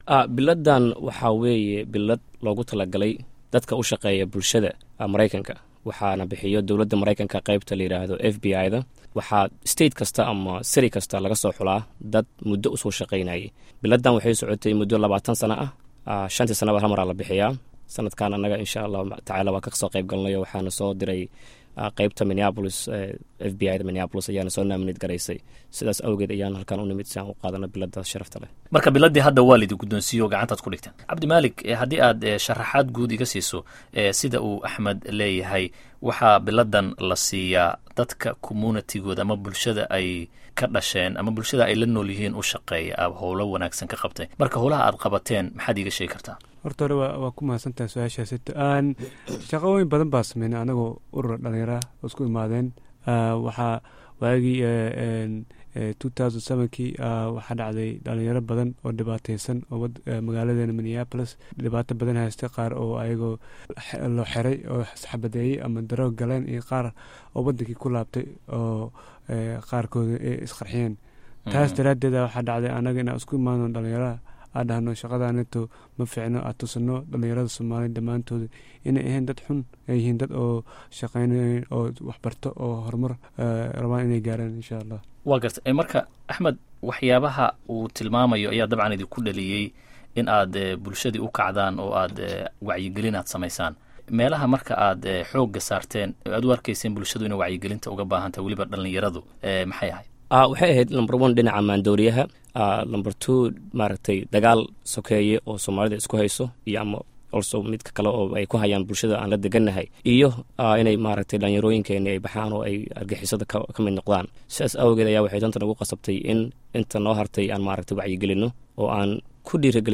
Dhageyso Warbixinta Abaal Marinta la siiyay Dhalinyarada Minnesota